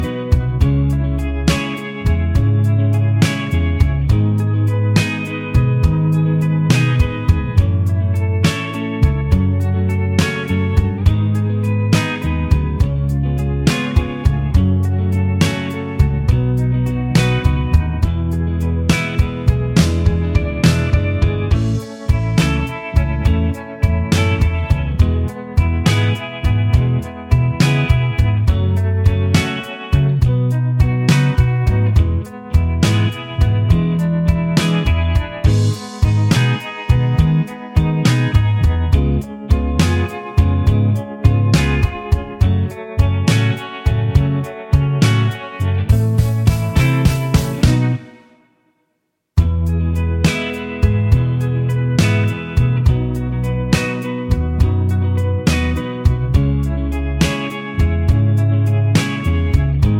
Cut Down Glam Rock 3:25 Buy £1.50